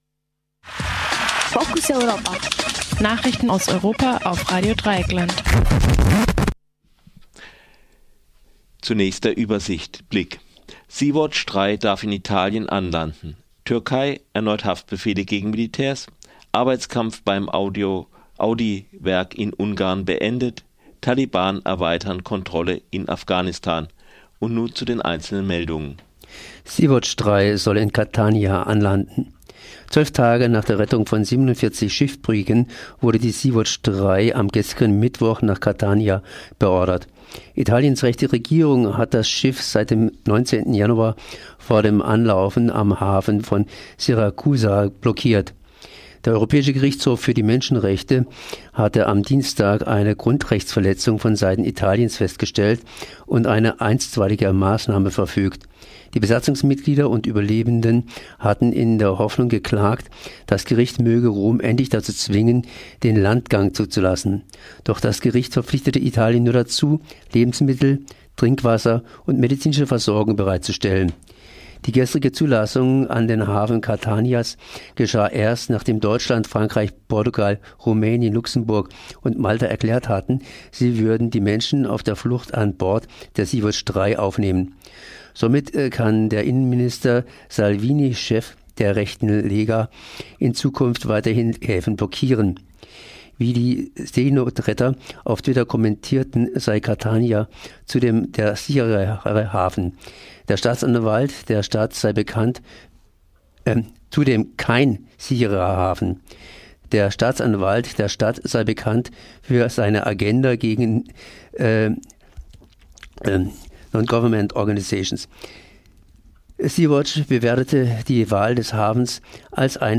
Focus Europa Nachrichten Donnerstag 31. Januar 2019